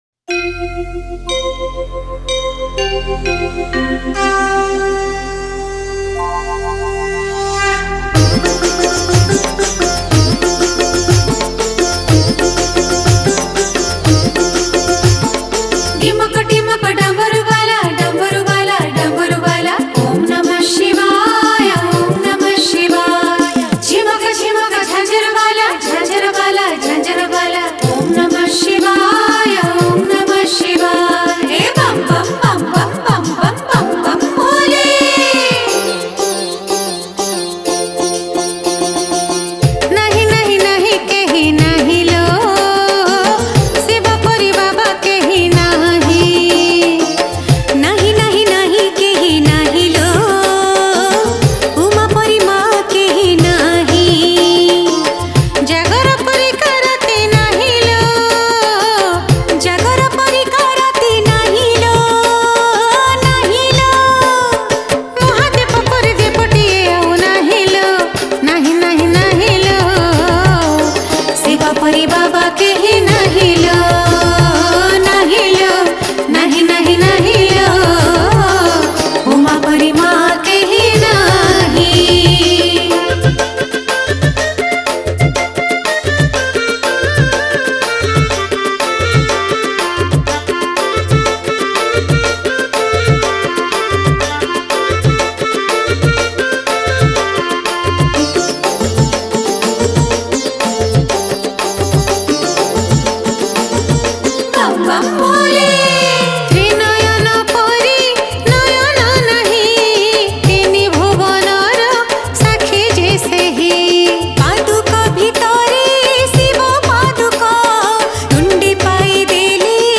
Jagara Special Odia Bhajan Song 2022